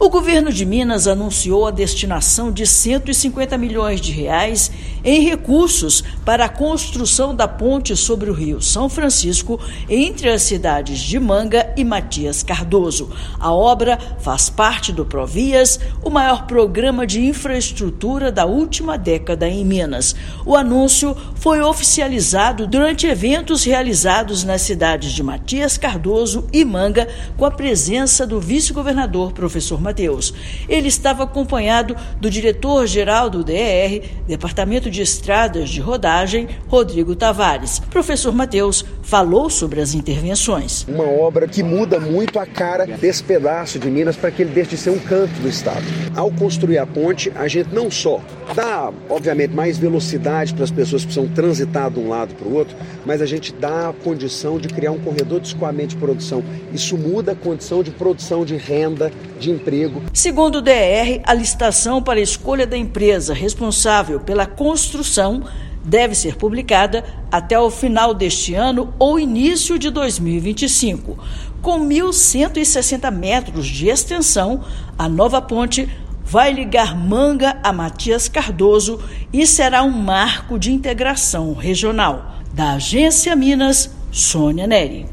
Obra vai melhorar o acesso entre os municípios e alavancar o desenvolvimento econômico da região. Ouça matéria de rádio.